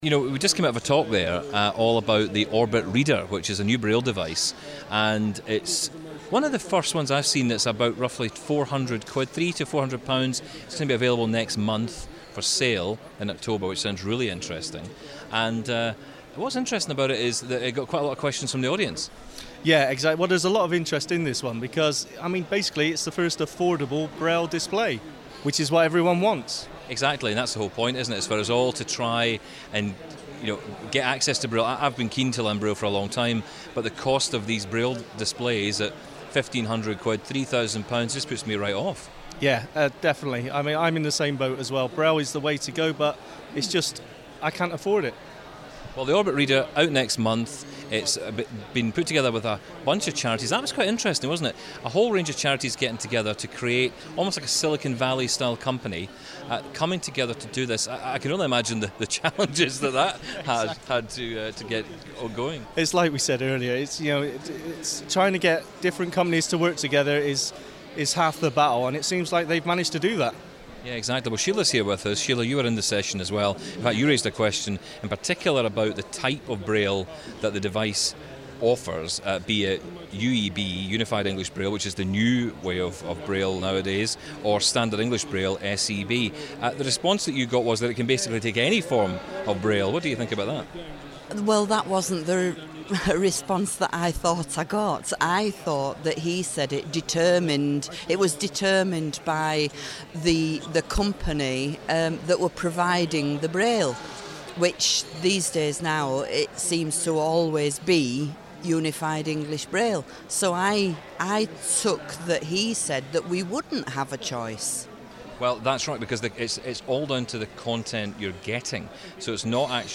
took to the exhibition floor at Techshare Europe 2016 to find out.